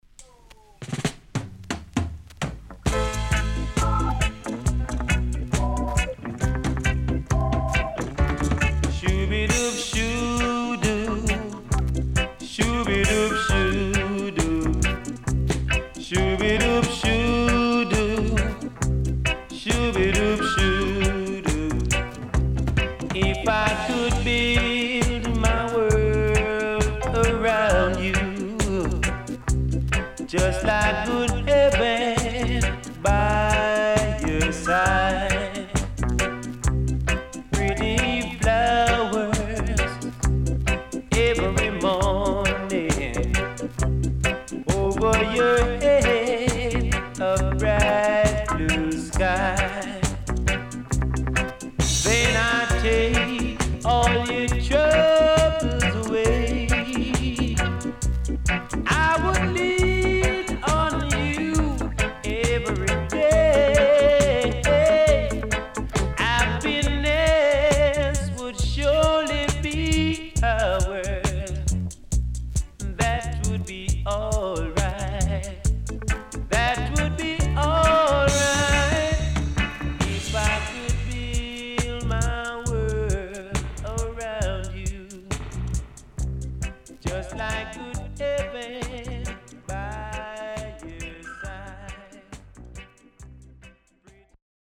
SIDE B:少しノイズ入ります。